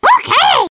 One of Toad's voice clips in Mario Kart 7